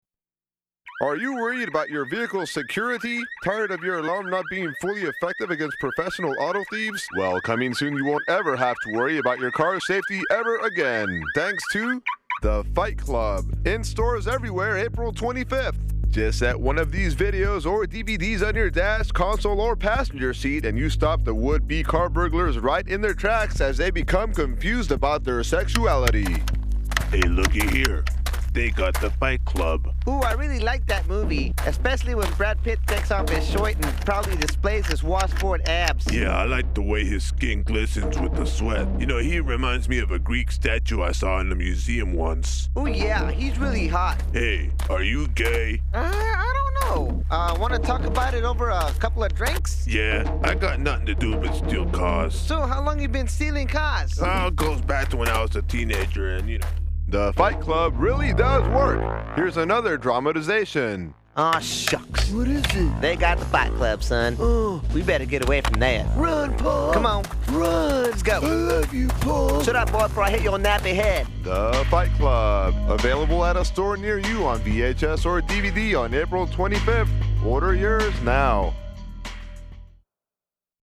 This is commercial parody I wrote, voiced and produced for a morning show as we gave away DVD copies of the modern classic, "Fight Club."